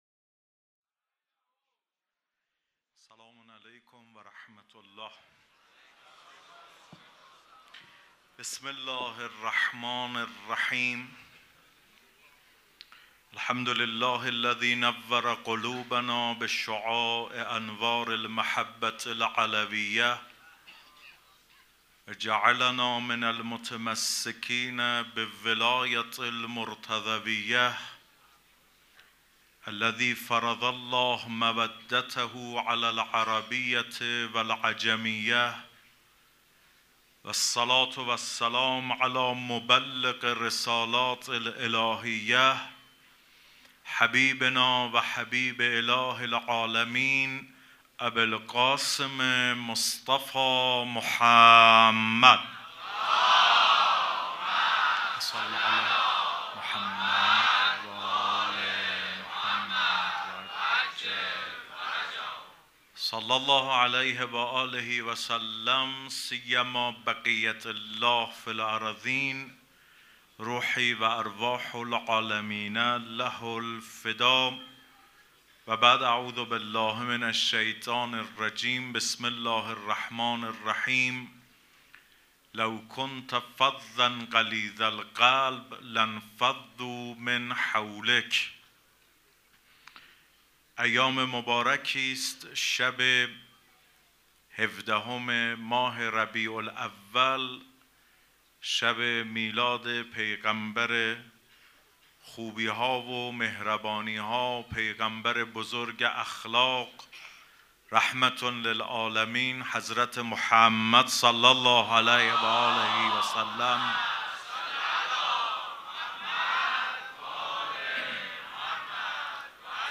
سخنرانی
سبک اثــر سخنرانی